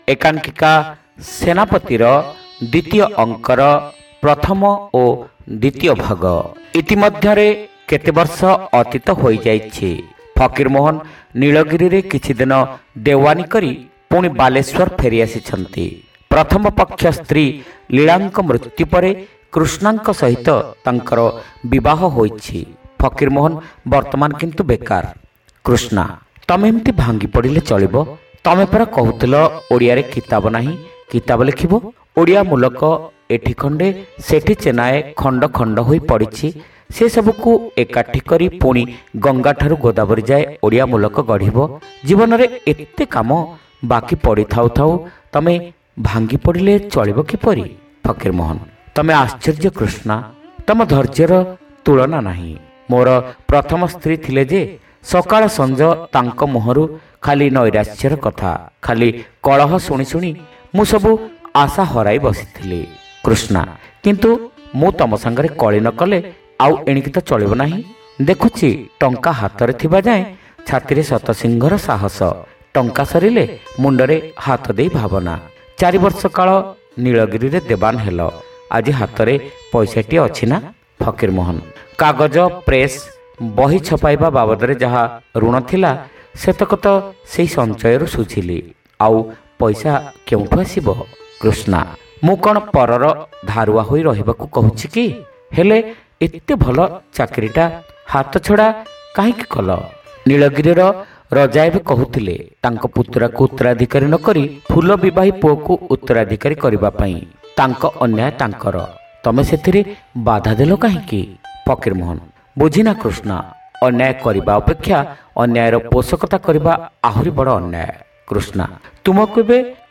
ଶ୍ରାବ୍ୟ ଏକାଙ୍କିକା : ସେନାପତି (ତୃତୀୟ ଭାଗ)